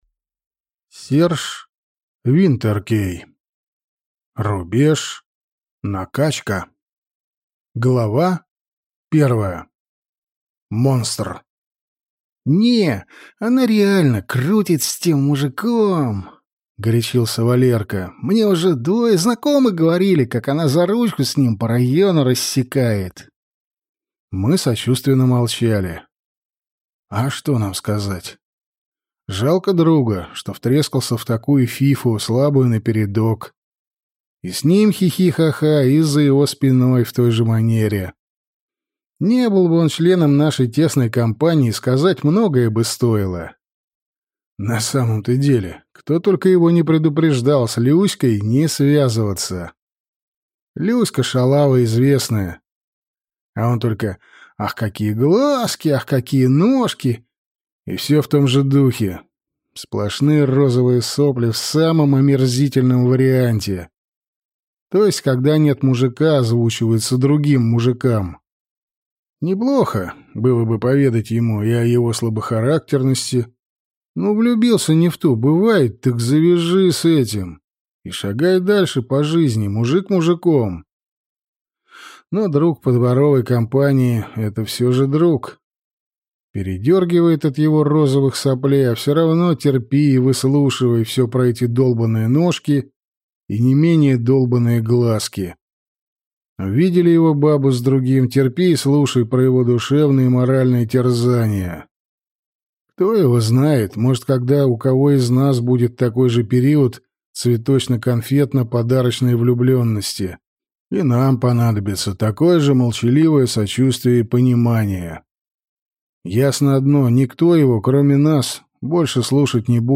Аудиокнига Рубеж: накачка | Библиотека аудиокниг